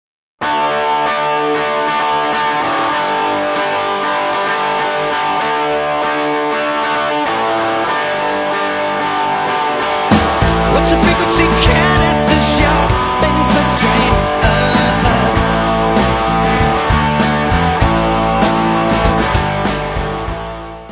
: stereo; 12 cm + folheto
Área:  Pop / Rock